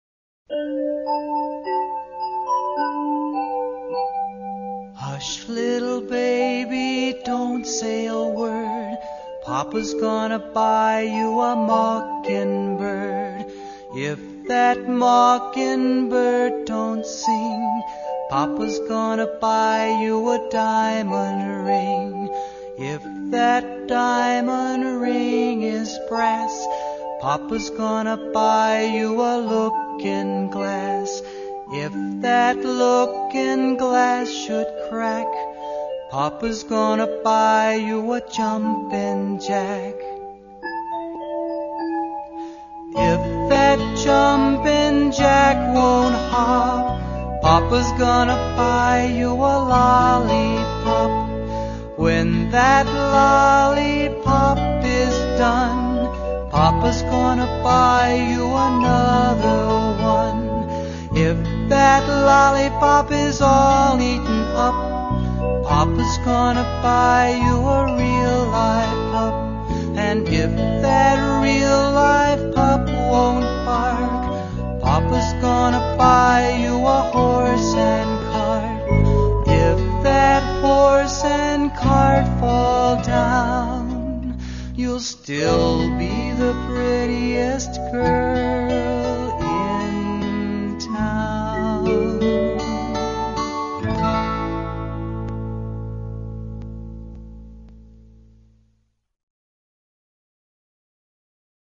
Nursery Songs